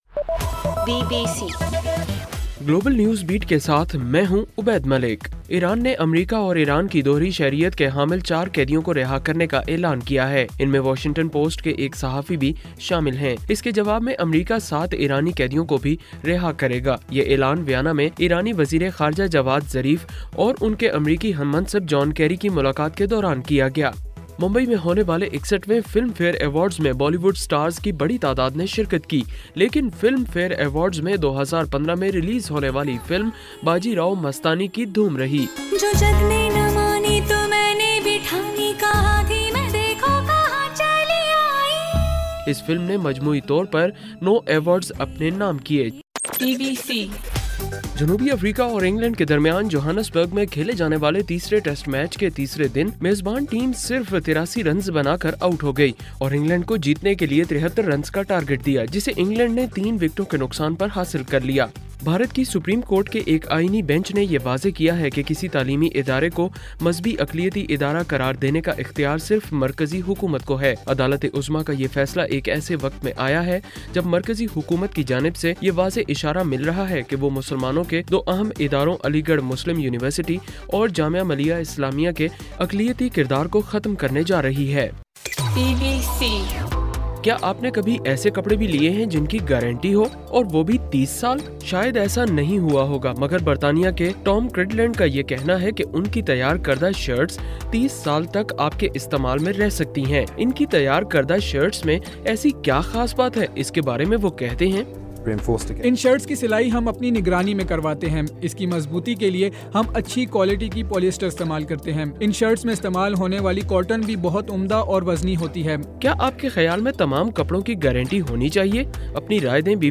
جنوری 16: رات 11 بجے کا گلوبل نیوز بیٹ بُلیٹن